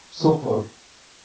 keyword-spotting
speech-commands